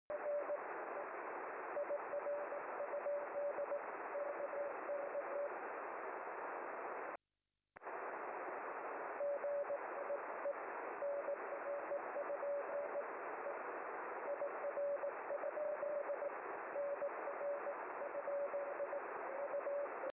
CW
Microwave contest, tnx